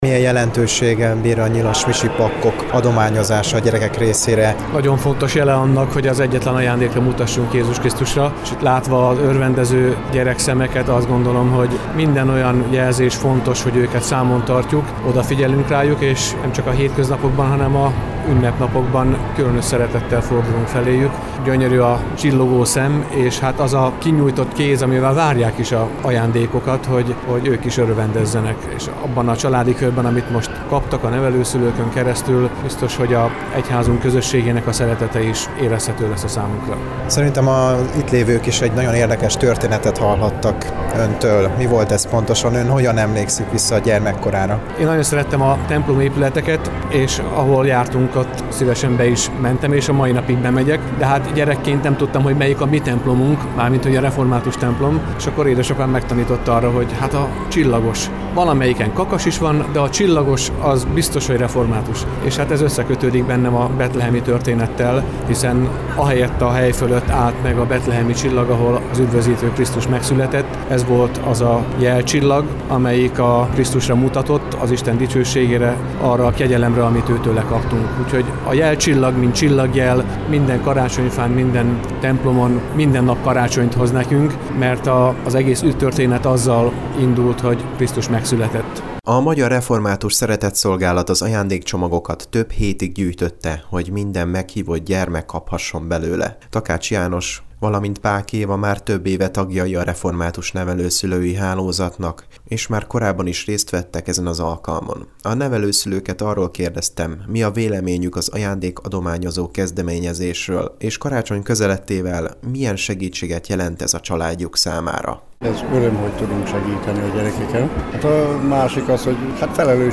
December 8-án 15:00 órától a Debreceni Nagytemplomban dr. Fekete Károly püspök igei szolgálatát követően a Debrecen-Árpád téri gyülekezet bábos csoportja szerzett vidám perceket a több mint 450 gyermeknek.